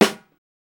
CDK Supper Snare.wav